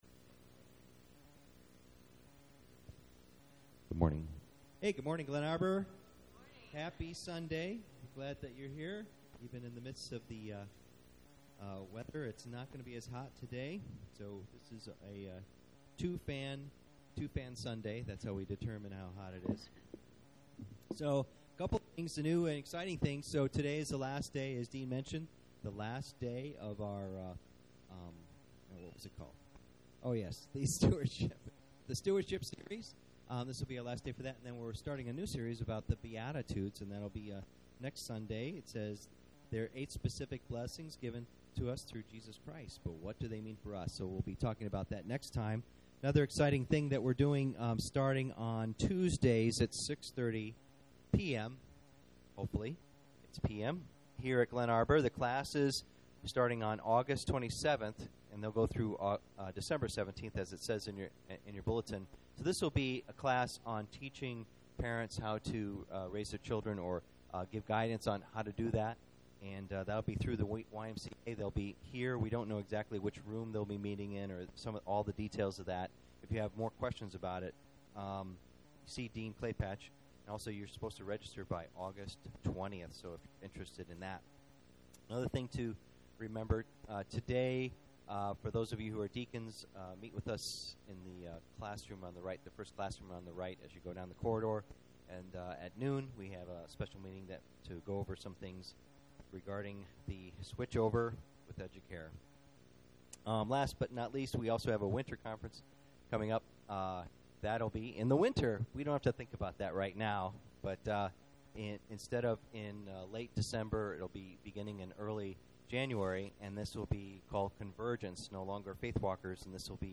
Series: Spiritual Gifts Service Type: Sunday Morning %todo_render% « Back to Basics